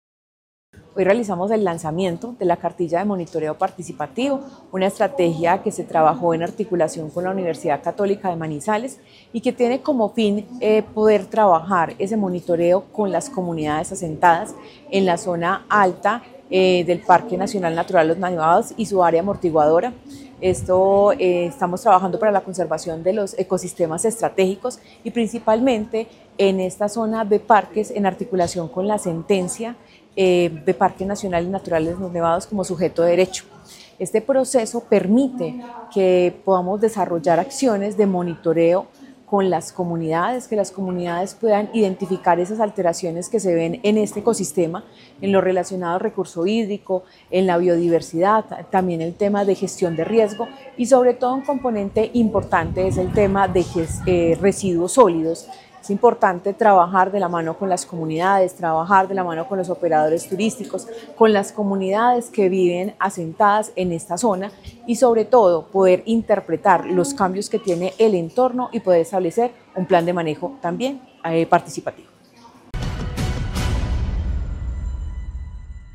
Secretaria de Medio Ambiente de Caldas, Paola Andrea Loaiza.